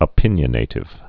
(ə-pĭnyə-nātĭv)